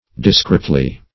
discretely - definition of discretely - synonyms, pronunciation, spelling from Free Dictionary Search Result for " discretely" : The Collaborative International Dictionary of English v.0.48: Discretely \Dis*crete"ly\, adv. Separately; disjunctively.